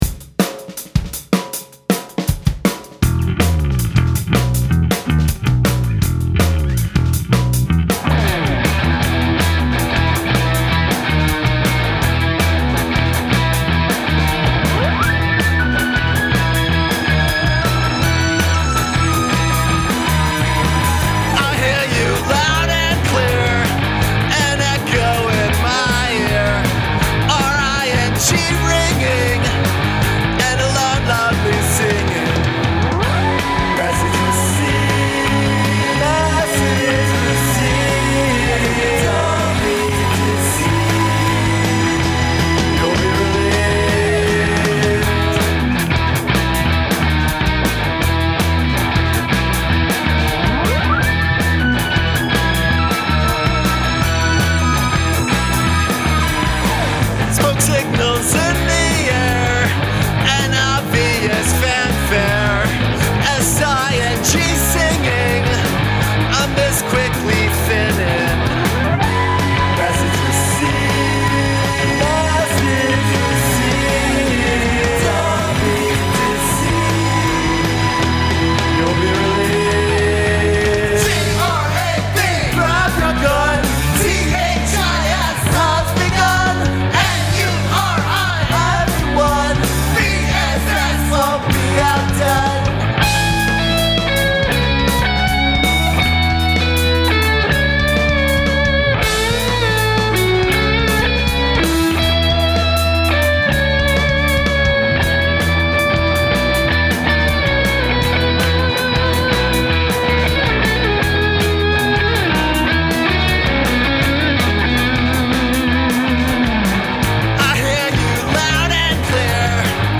Use of spelling in a song.